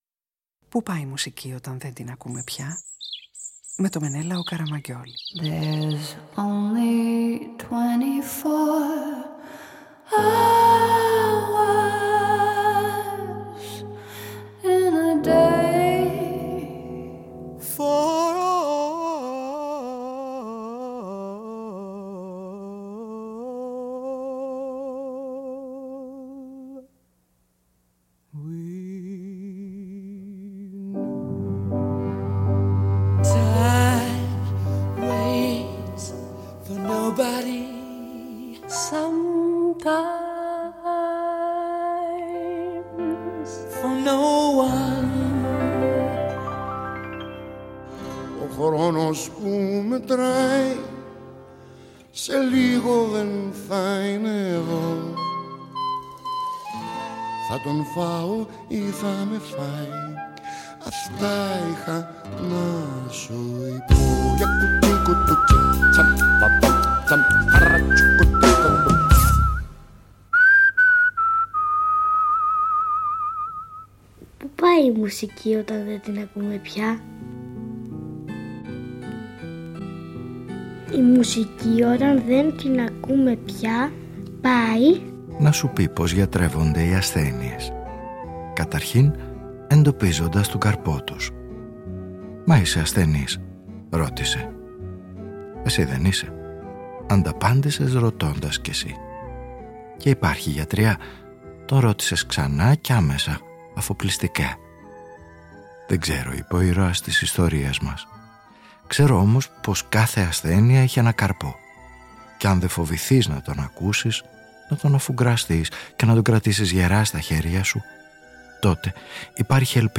Πως γιατρεύονται οι ασθένειες; Καταρχήν εντοπίζοντας τον καρπό τους επιμένει ο ήρωας της σημερινής ραδιοφωνικής ταινίας που ψάχνει να εντοπίσει το ευεργέτημα της αστάθειάς μας και κάθε εφικτή θεραπεία σε μια παγκόσμια απειλή.